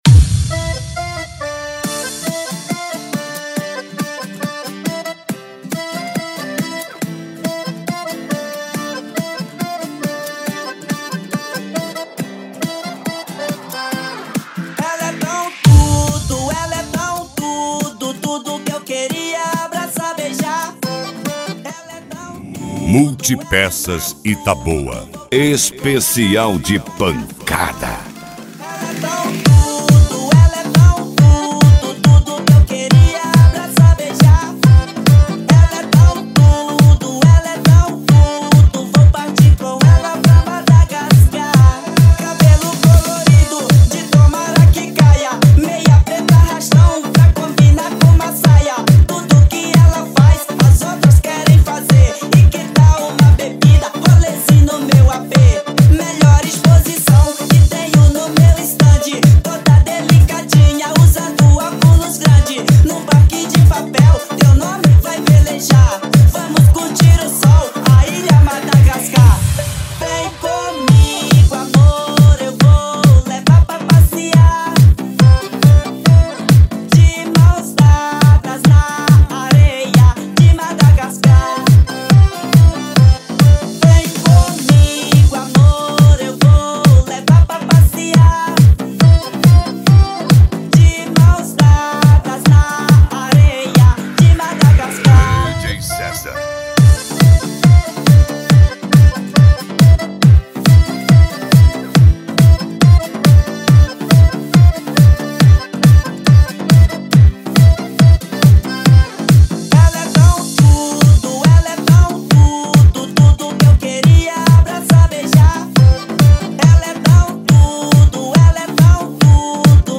PANCADÃO